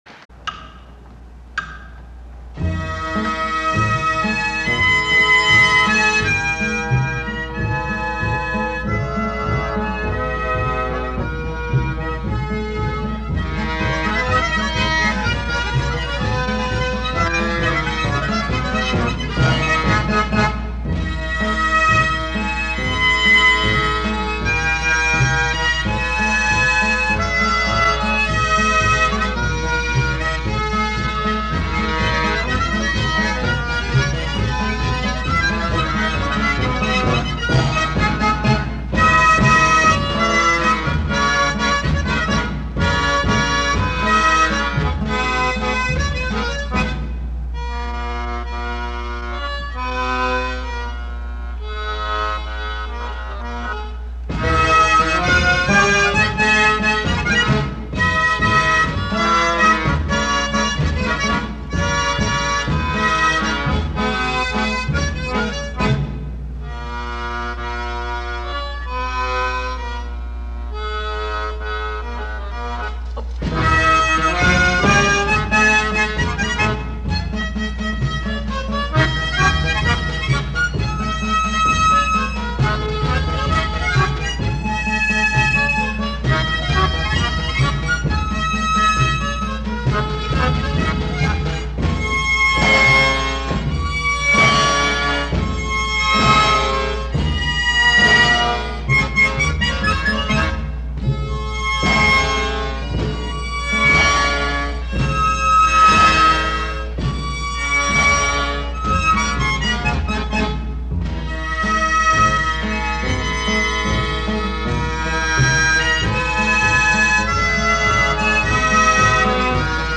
National Sanatorium Nagashima Aiseien, Okayama